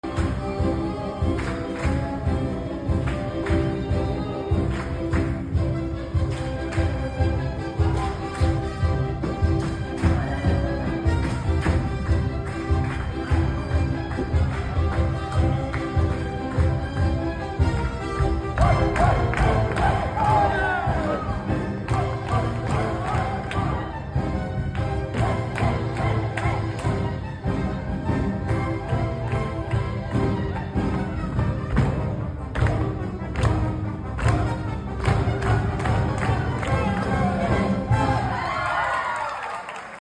Backstage/stage right life as a sound engineer.